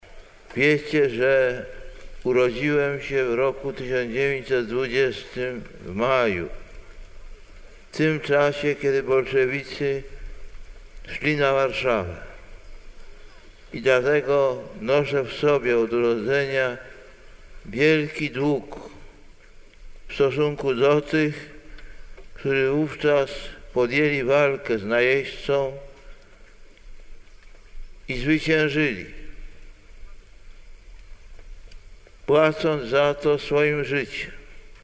Radzymin k. Warszawy
radzymin-1999.mp3